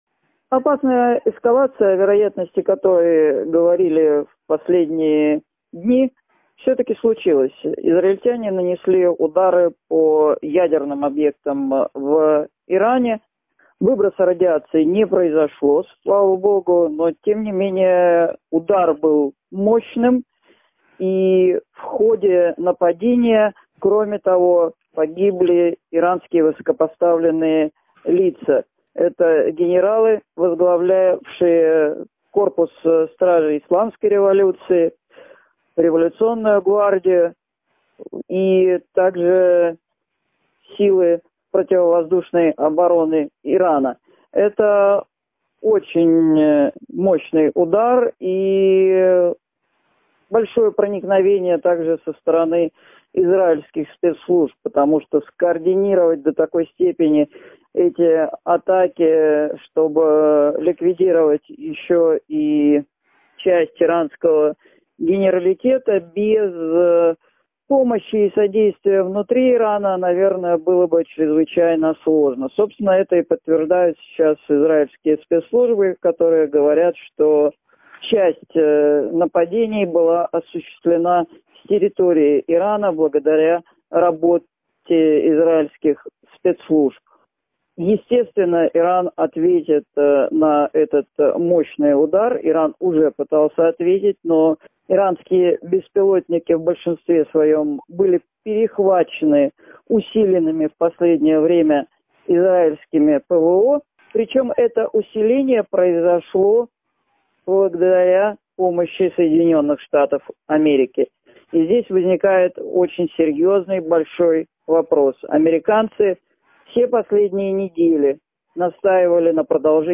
ГЛАВНАЯ > Актуальное интервью
в интервью журналу «Международная жизнь» рассказала о резком обострении ирано-израильского конфликта: